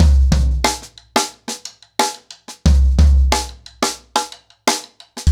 InDaHouse-90BPM.37.wav